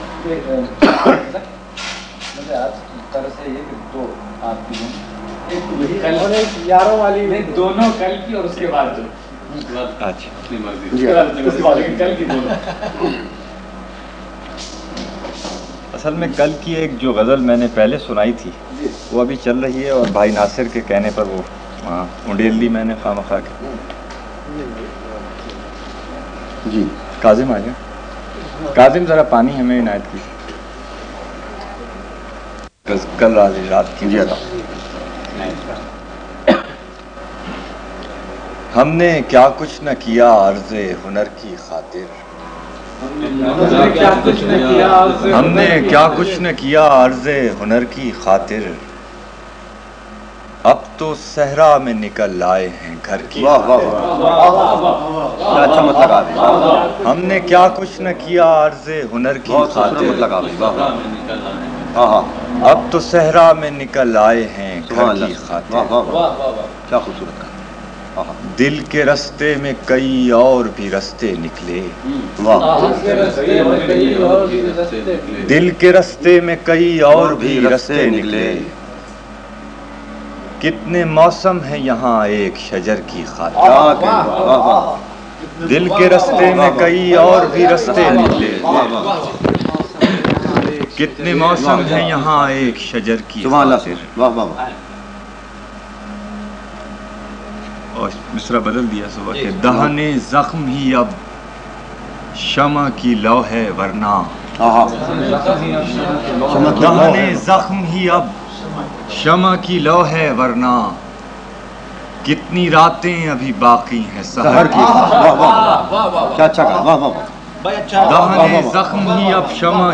one of the few that he recorded at my house.